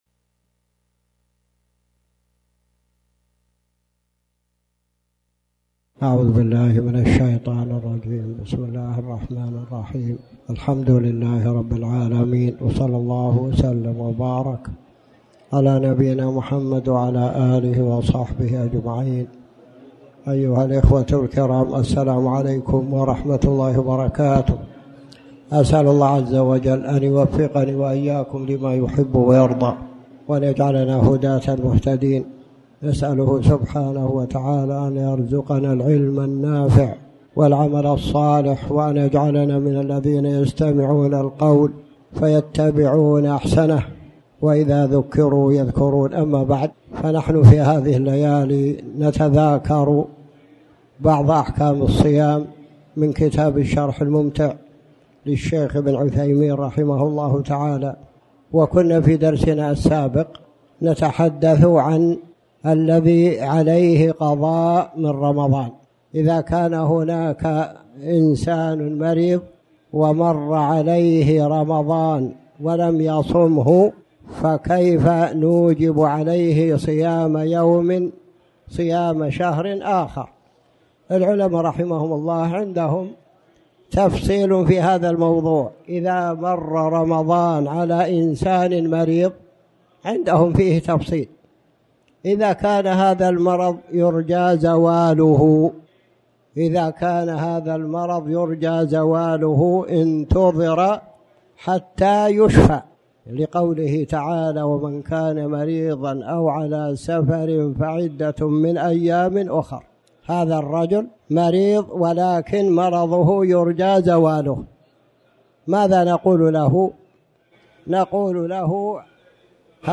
تاريخ النشر ١٢ شعبان ١٤٣٩ هـ المكان: المسجد الحرام الشيخ